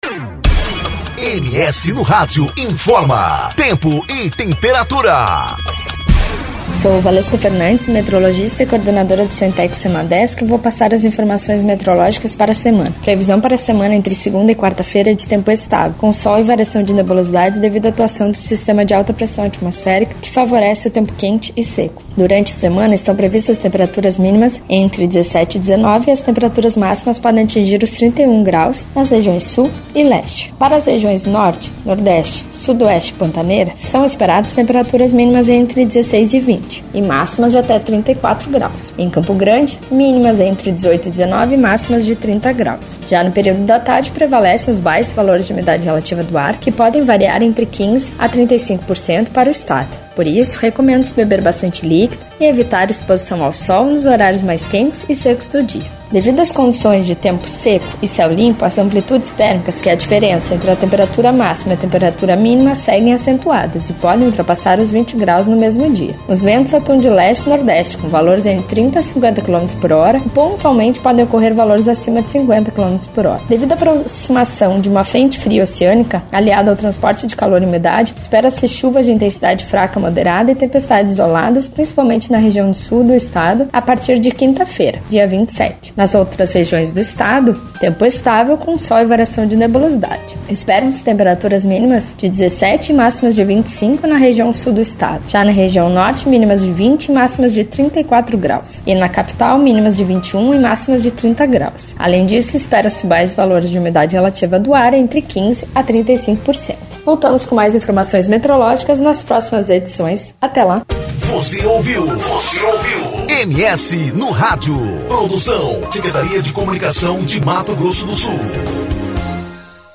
• Rádio